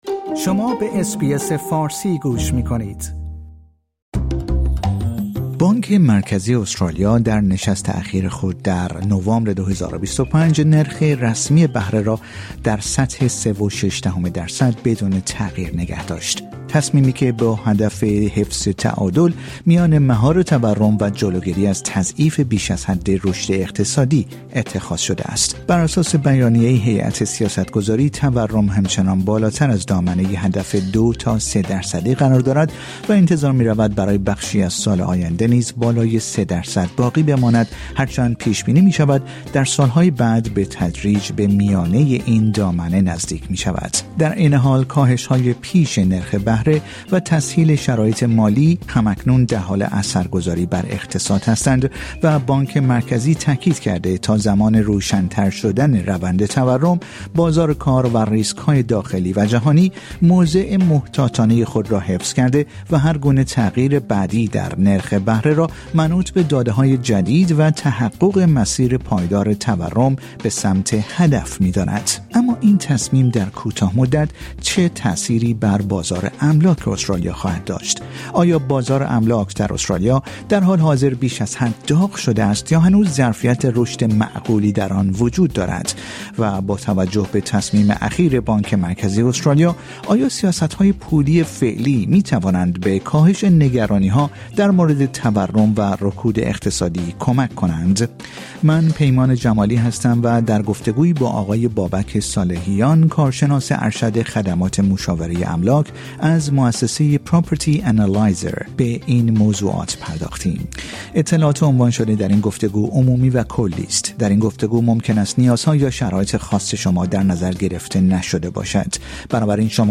اطلاعات عنوان شده در این گفتگو، عمومی و کلی است.